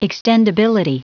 Prononciation du mot : extendability